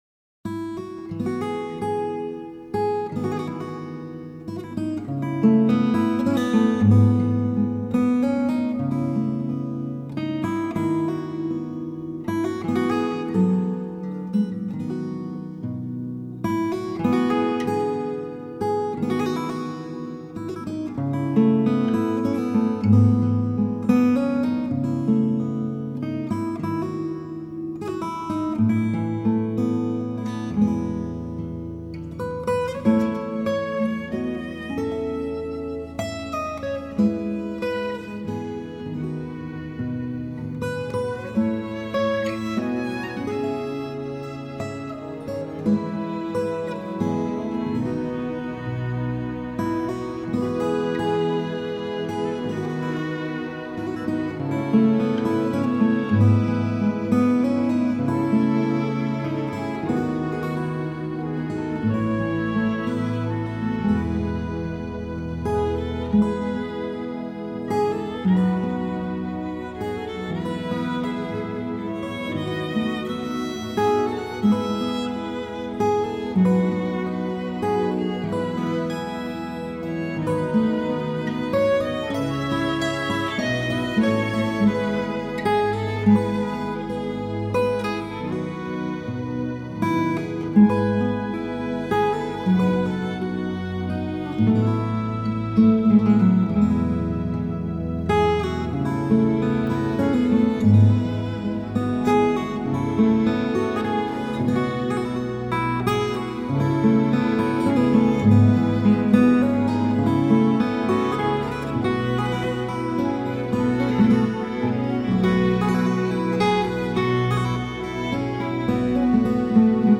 【所屬類別】 CD唱片　　新世紀